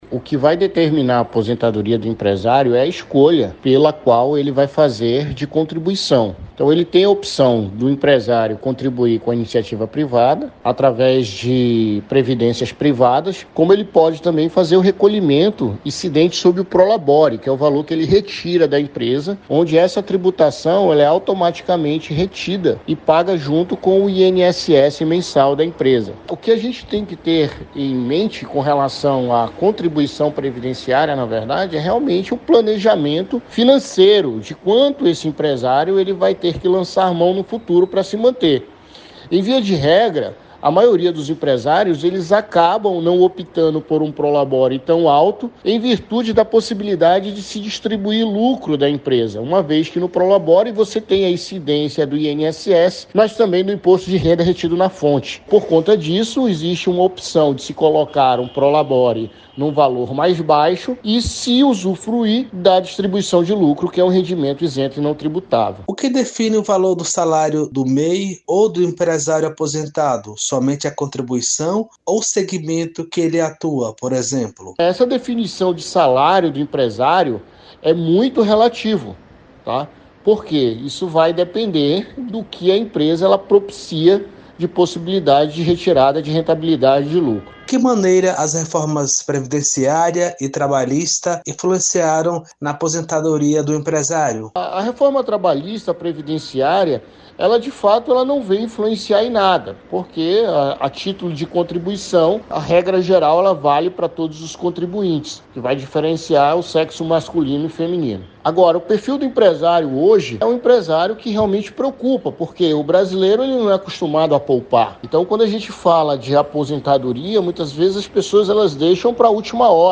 Especialista em gestão tributária